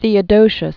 (thēə-dōshəs, -shē-əs) Known as "Theodosius the Great."